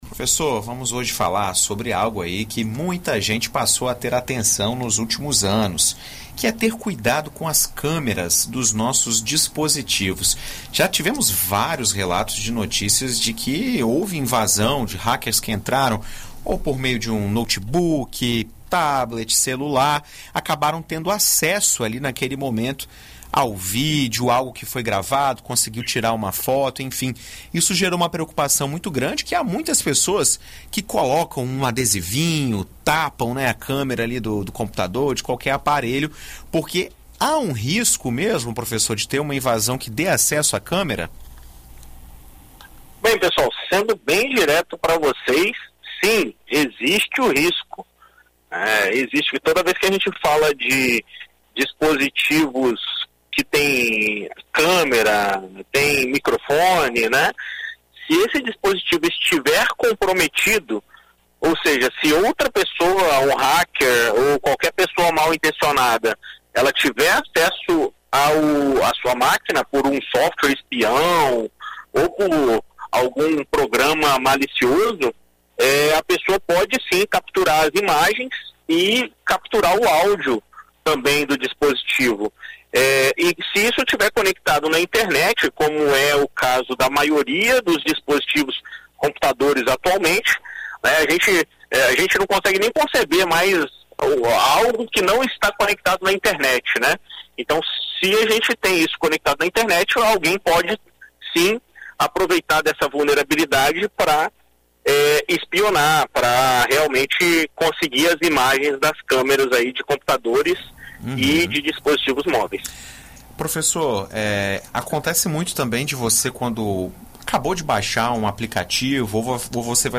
Em entrevista à BandNews FM Espírito Santo nesta terça-feira (10)